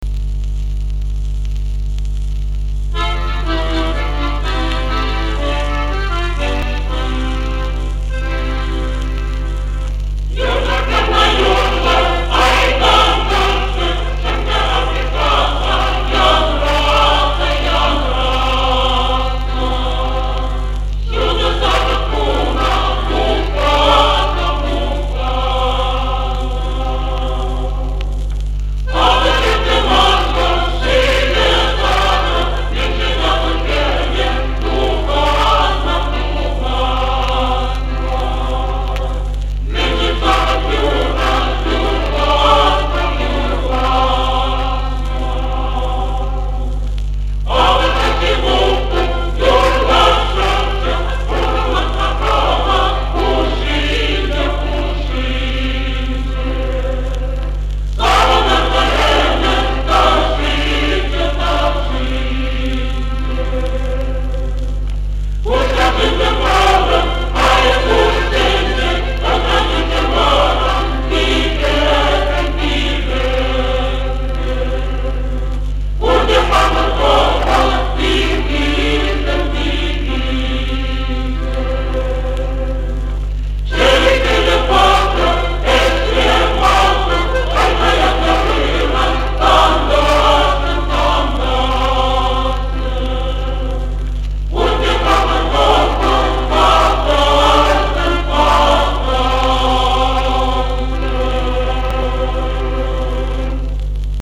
Описание: Песня на чувашском
Баянист